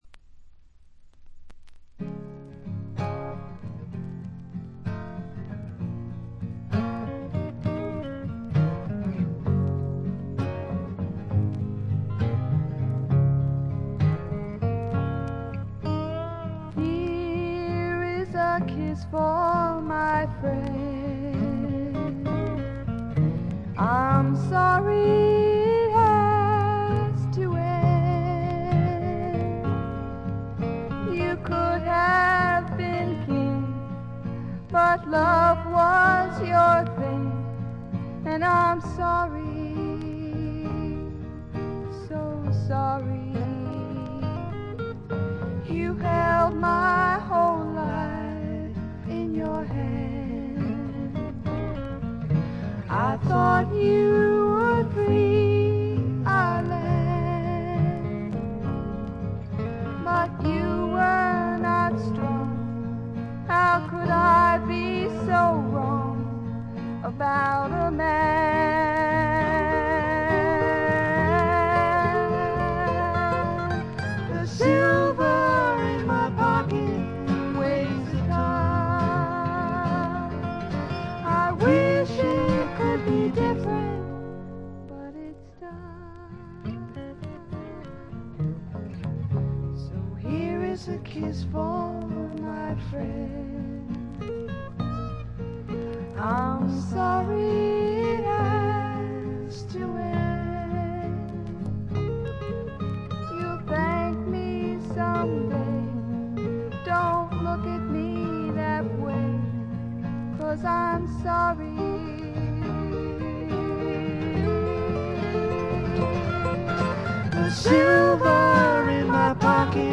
バックグラウンドノイズ、チリプチやや多めですが鑑賞を妨げるようなものはありません。
試聴曲は現品からの取り込み音源です。